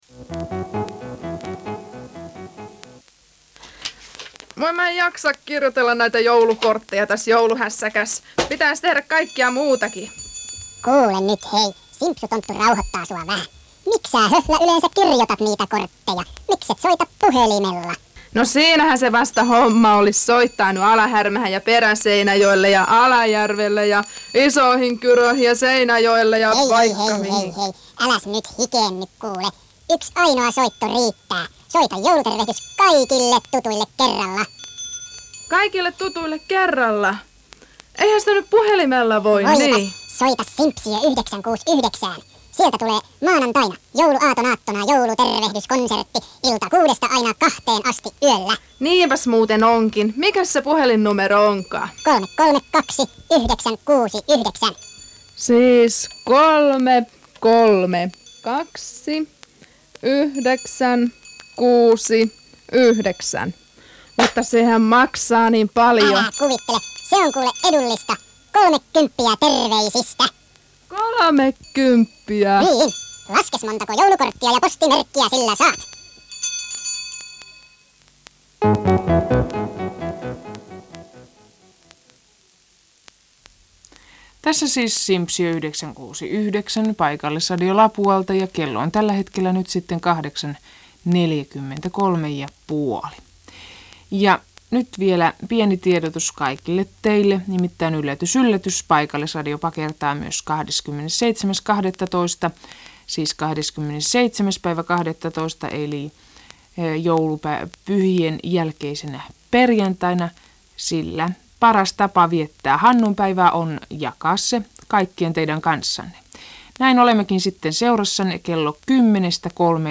Simpsiö 96,9:n ohjelmamainos sekä juontoa ja aseman tunnusmelodia 17.12.1985. Jos soitin ei toimi, voit kuunnella äänitiedoston tästä.